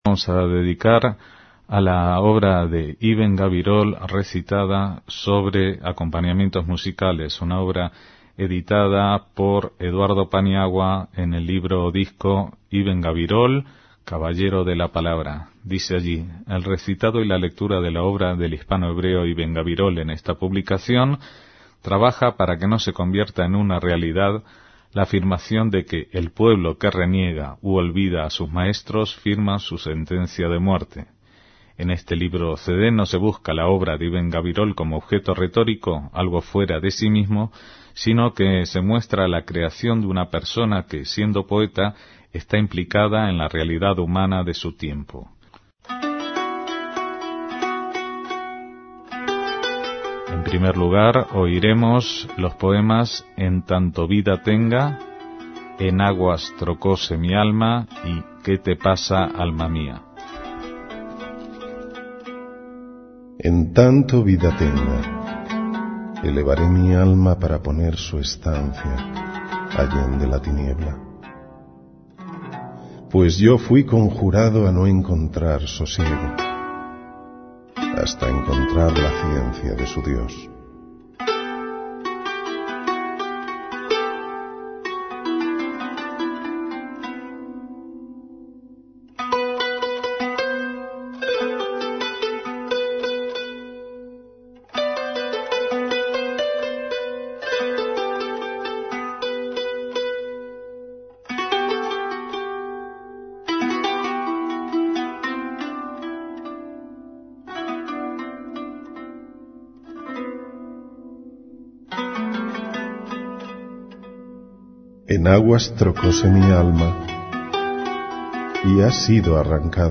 Recitados